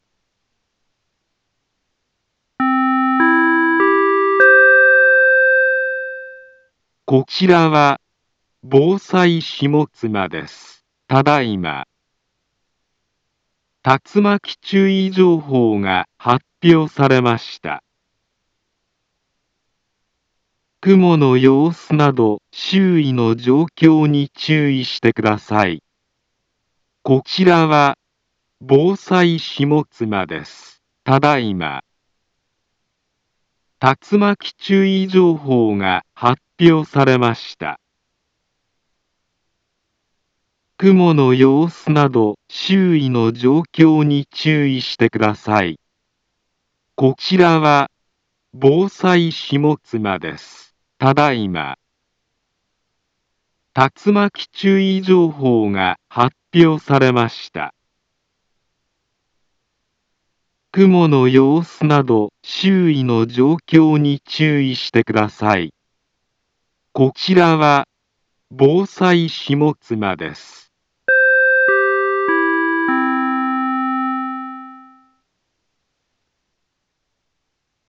Back Home Ｊアラート情報 音声放送 再生 災害情報 カテゴリ：J-ALERT 登録日時：2024-08-17 17:14:32 インフォメーション：茨城県南部は、竜巻などの激しい突風が発生しやすい気象状況になっています。